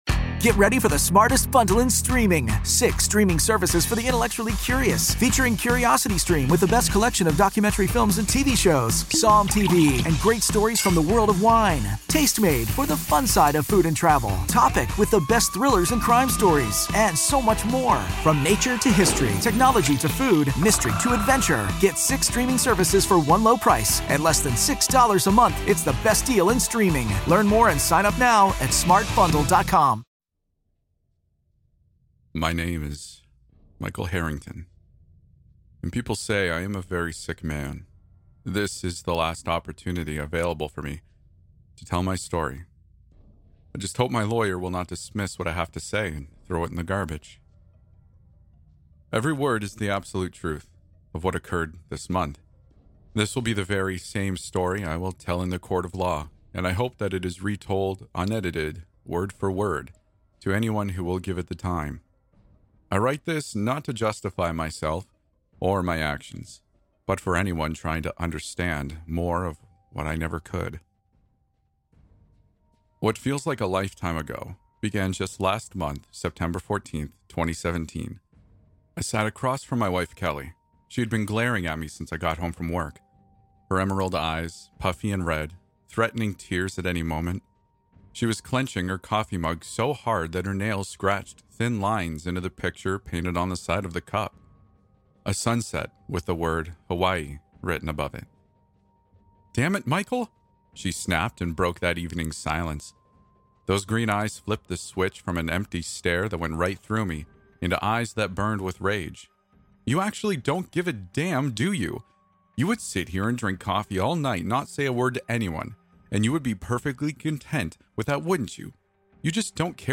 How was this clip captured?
As per your request, I have remastered and re-recorded Daddy's Girl! This particularly creepy reddit story is the highest viewed video on the channel.